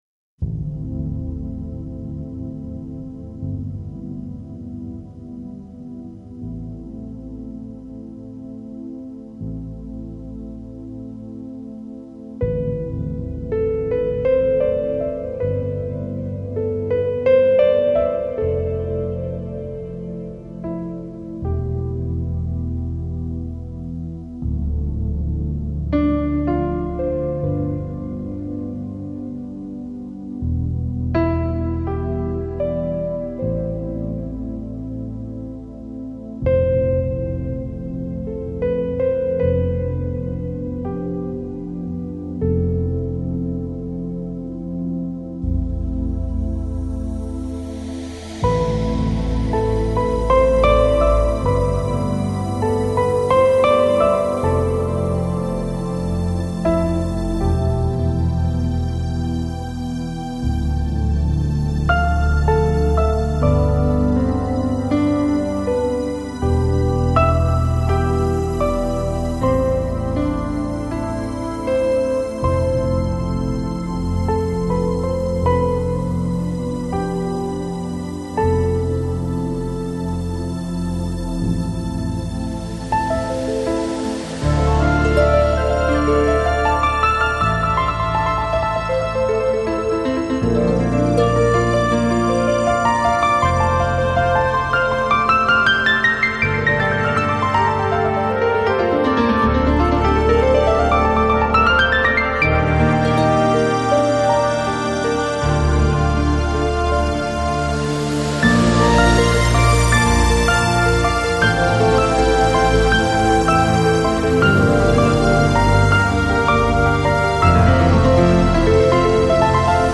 Lounge, Chill Out, Downtempo, Ambient, Relax Носитель